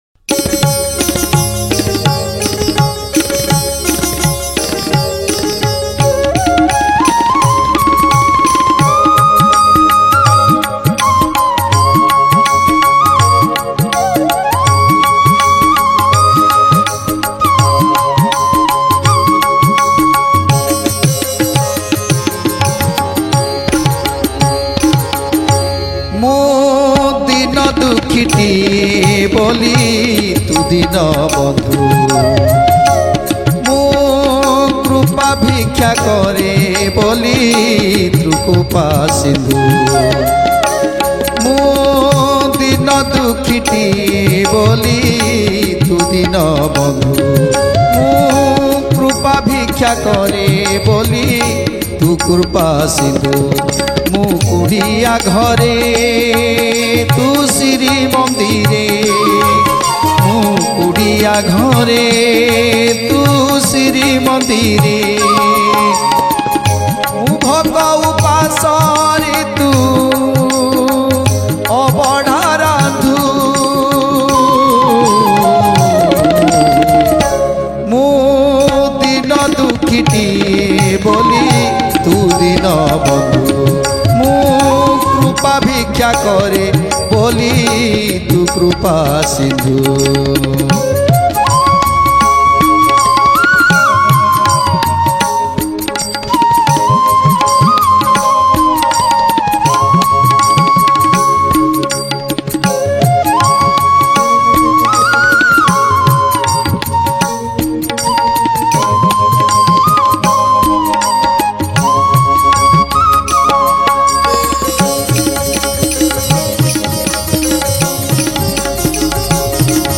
Odia Bhajan Song